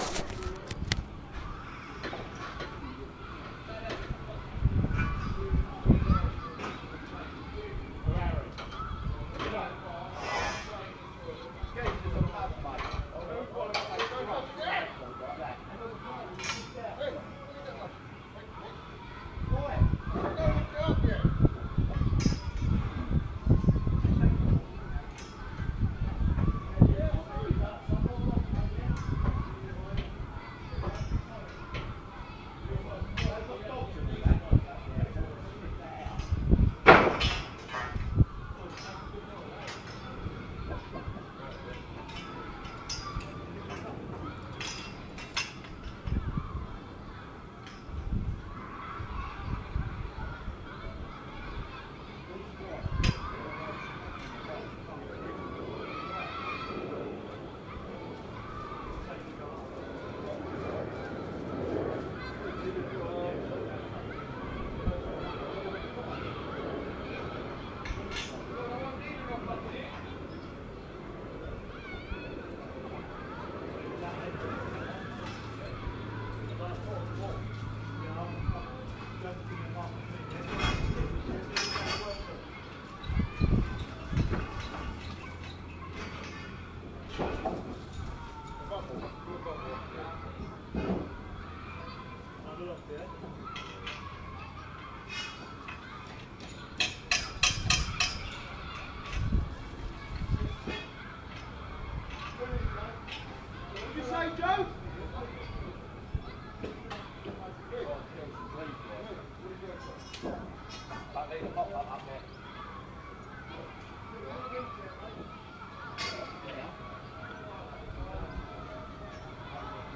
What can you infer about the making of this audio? LayingWithMorningStockwellNoises